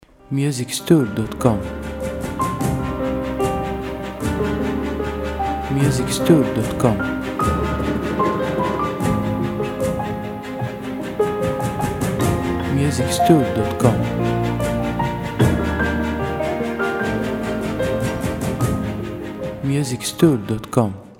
• Type : Instrumental
• Bpm : Andante
• Genre : Ambient & Cinematic / Romantic Soundtrack